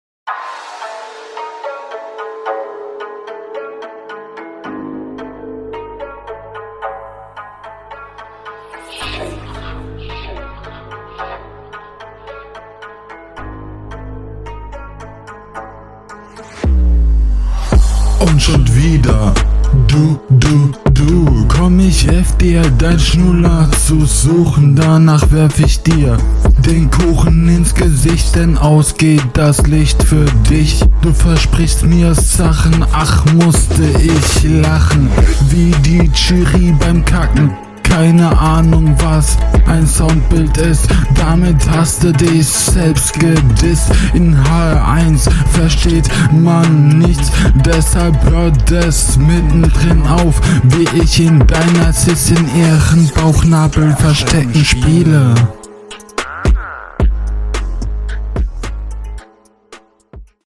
Du kommst hier nicht so gut rüber wie dein Gegner, du bist unsynchron.
Die Audio klingt nicht sonderlich gut.